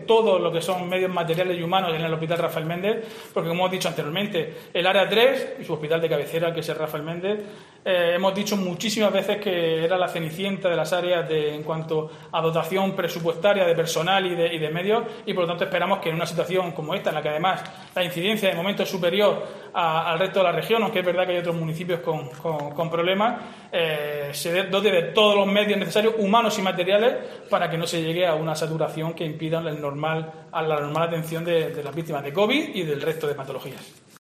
Diego José Mateos, alcalde de Lorca sobre Hospital Rafael Méndez